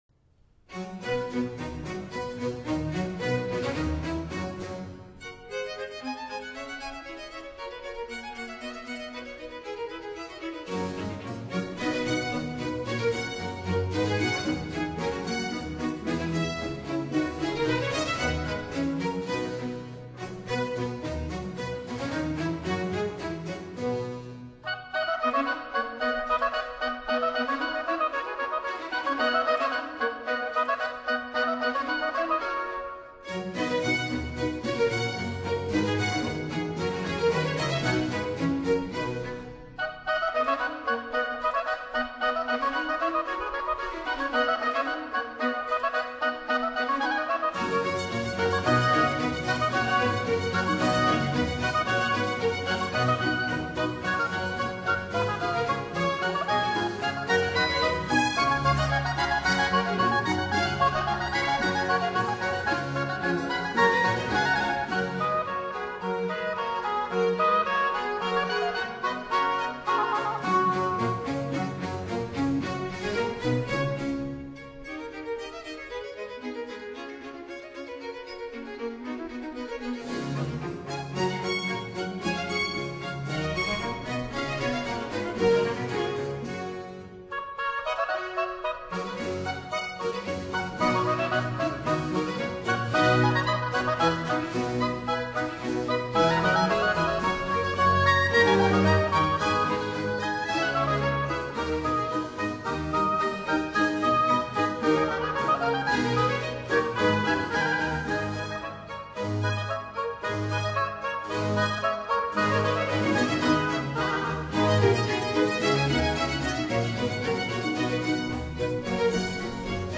Allegro    [0:03:53.50]